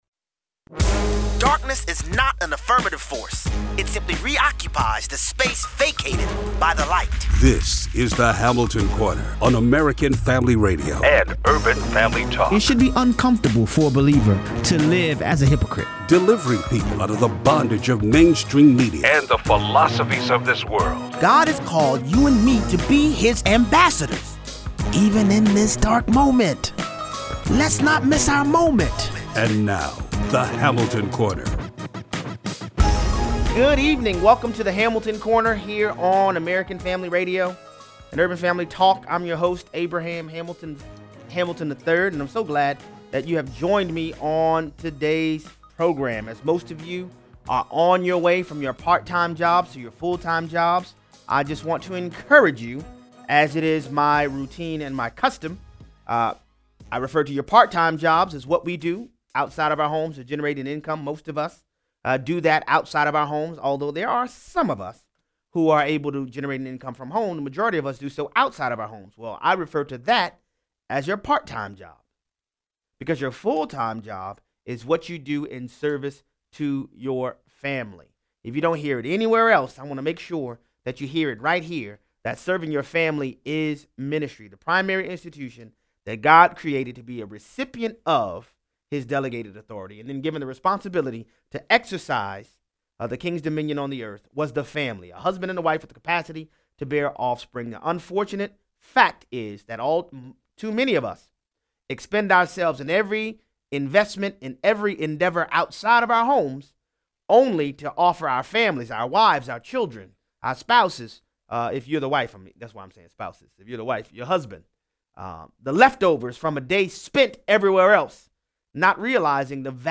Callers weigh in.